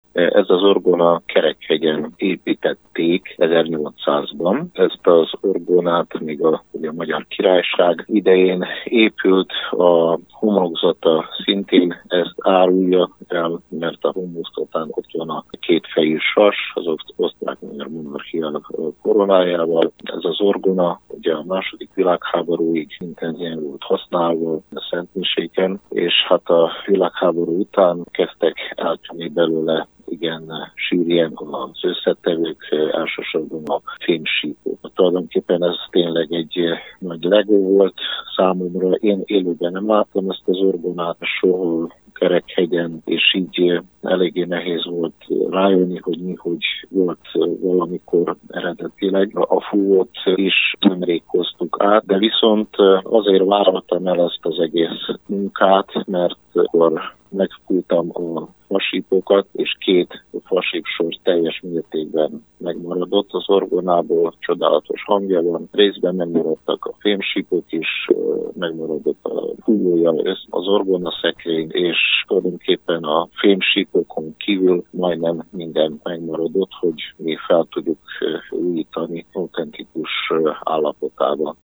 orgona01.mp3